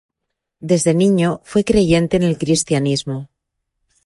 Pronounced as (IPA)
/kɾistjaˈnismo/